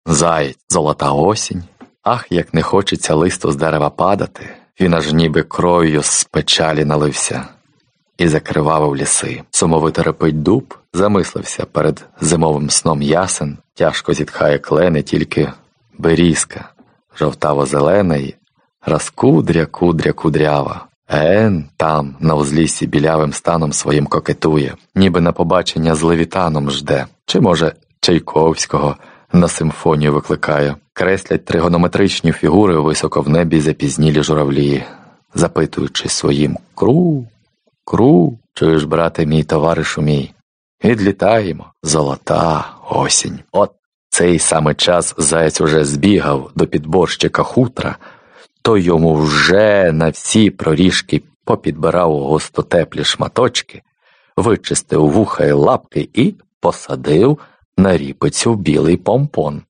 Аудиокнига Мисливські усмішки | Библиотека аудиокниг